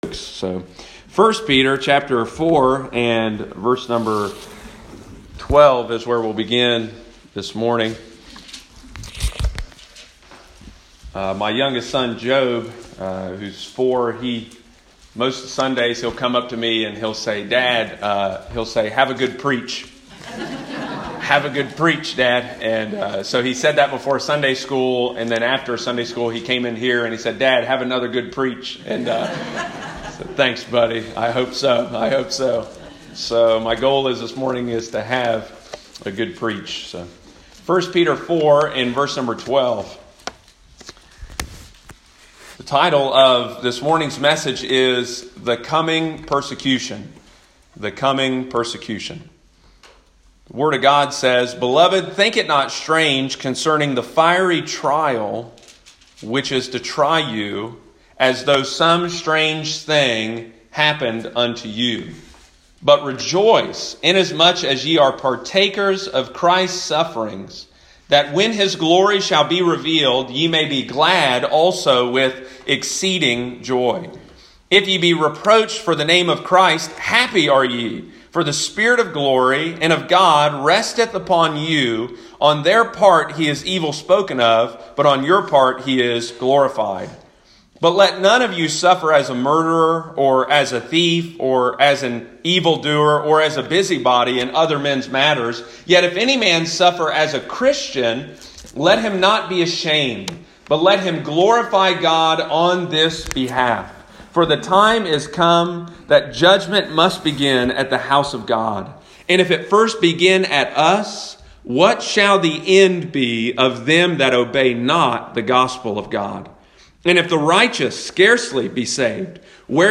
The Coming Persecution – Lighthouse Baptist Church, Circleville Ohio